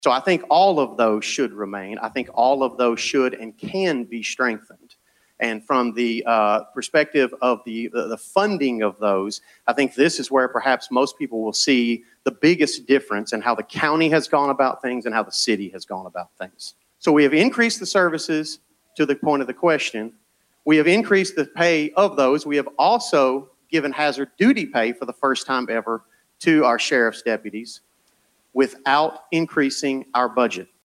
The League of Women Voters hosted the candidates Monday night for its final forum of the night.